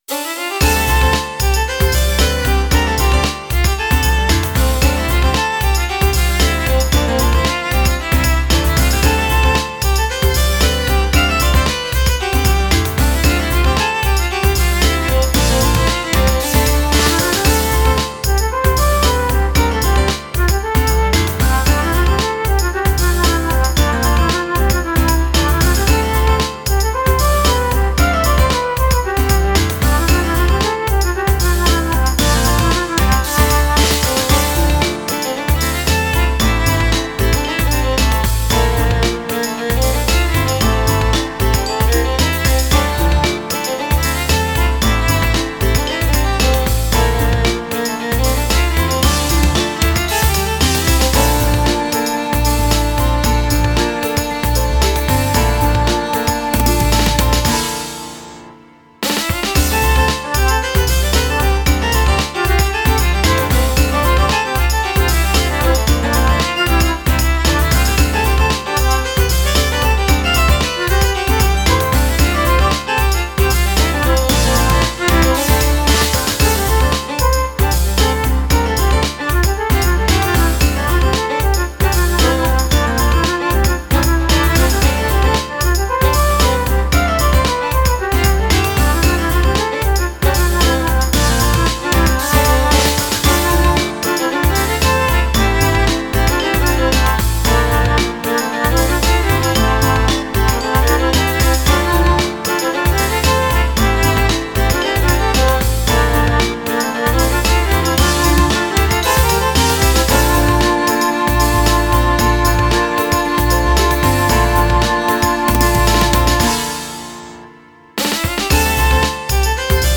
イメージ：風の舞うフィールド　ジャンル：ケルト音楽、異世界
コメント：ケルト系、ミディアムテンポの曲です。